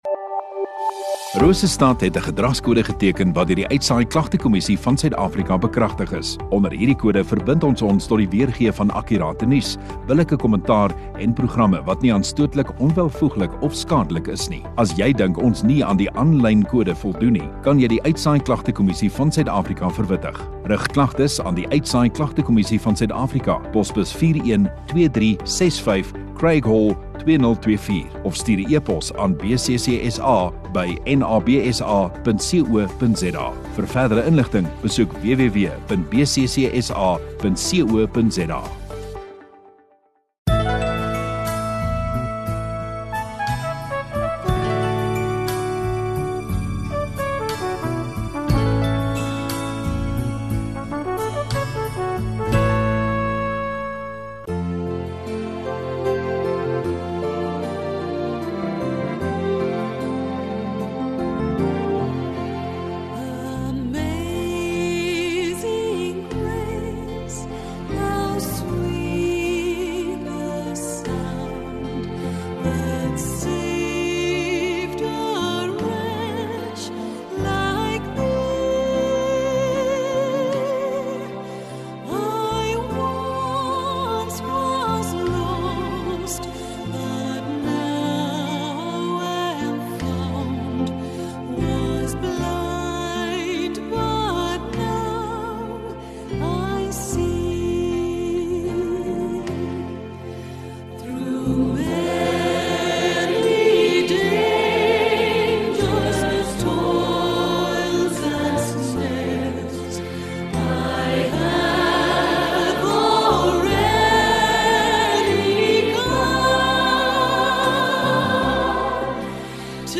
22 Jun Saterdagoggend Oggenddiens